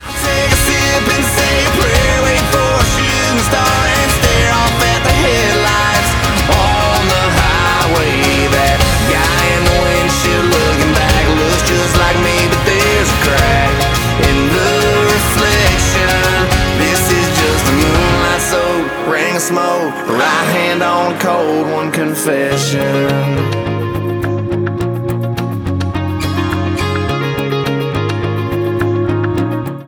• Country
American country music duo